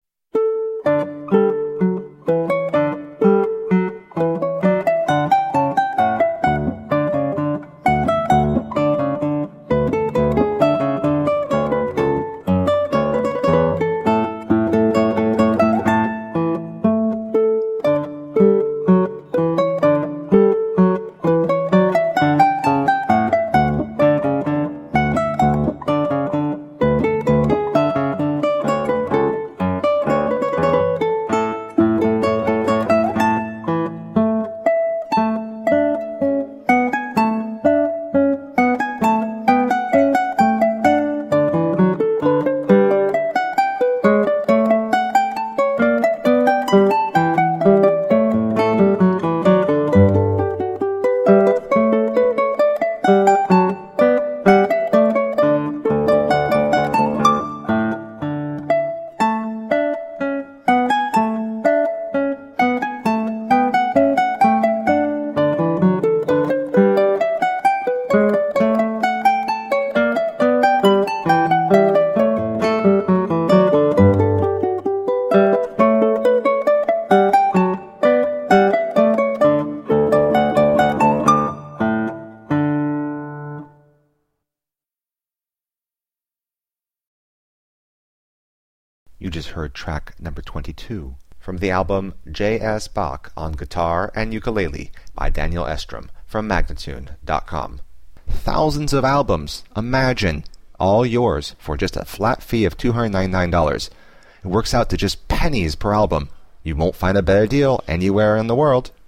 Classical, Baroque, Instrumental
Classical Guitar, Ukulele